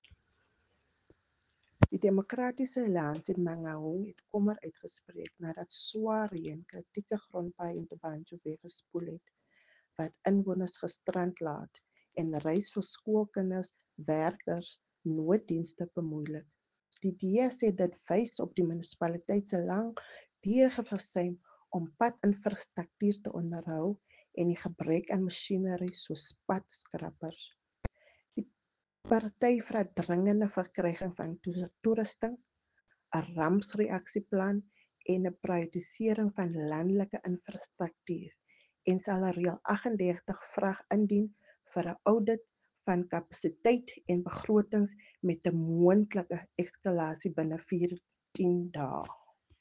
Afrikaans soundbite by Cllr Raynie Klaasen